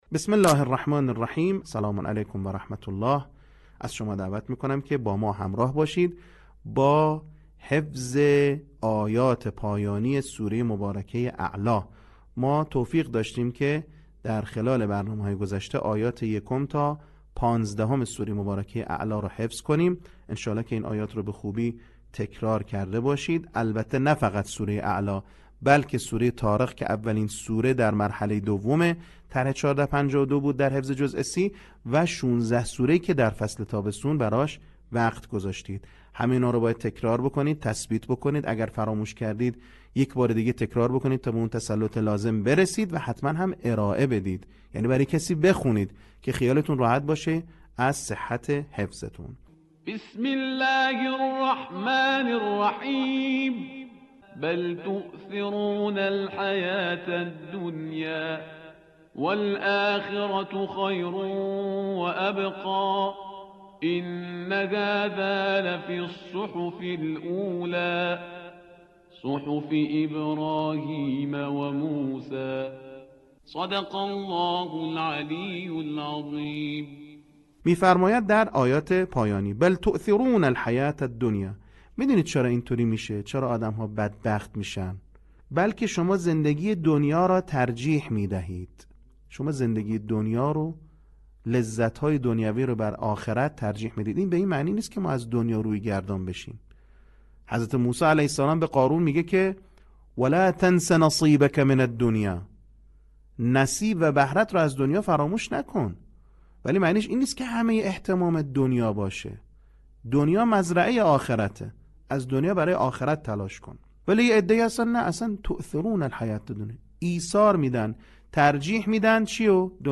صوت | بخش چهارم آموزش حفظ سوره اعلی
آموزش قرآن